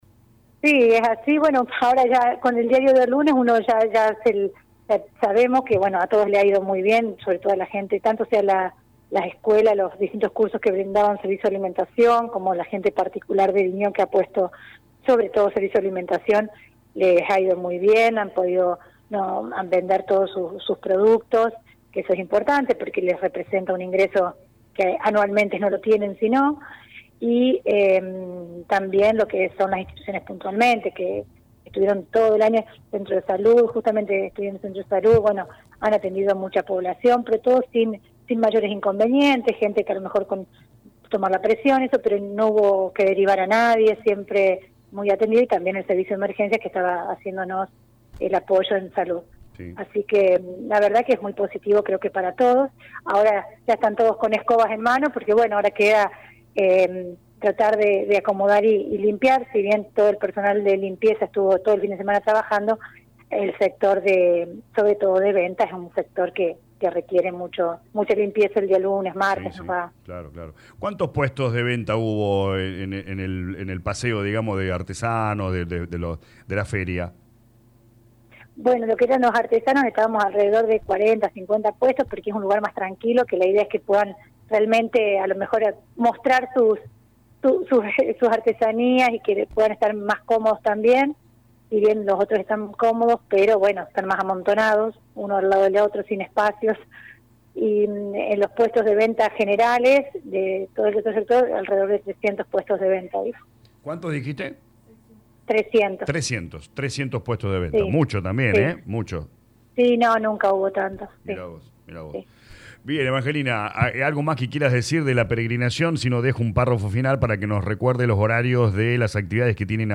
Así lo confirmó la intendente de Colonia Vignaud Lic. Evangelina Vigna en diálogo con LA RADIO 102.9 FM y afirmó que fue «un fin de semana muy lindo, muy concurrido y con un clima que ayudó a que todo se desarrolle de la mejor manera».